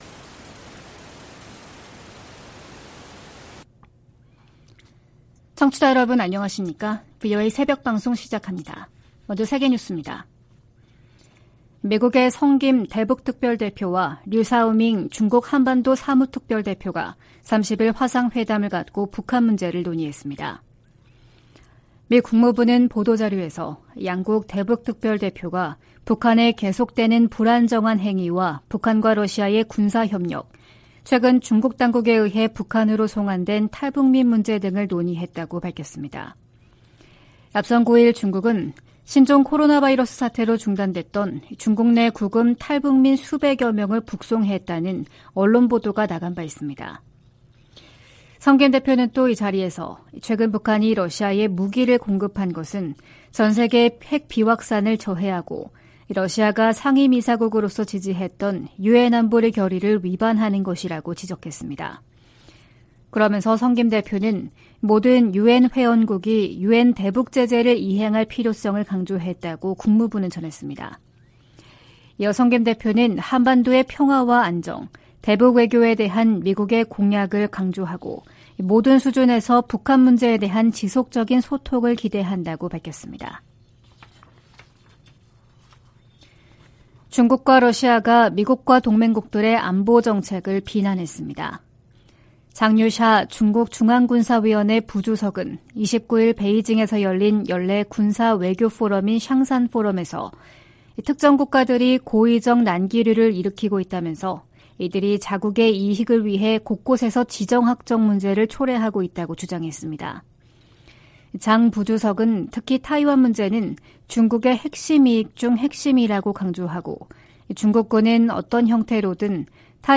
VOA 한국어 '출발 뉴스 쇼', 2023년 10월 31일 방송입니다. 유엔총회 제1위원회가 북한의 핵무기와 대량살상무기 폐기를 촉구하는 내용이 포함된 결의안 30호를 통과시키고 본회의에 상정했습니다. 하마스가 이스라엘 공격에 북한제 대전차무기를 사용하고 있다고 중동문제 전문가가 말했습니다. 미 국무부는 북한과의 무기 거래를 부인한 러시아의 주장을 일축하고, 거래 사실을 계속 폭로할 것이라고 강조했습니다.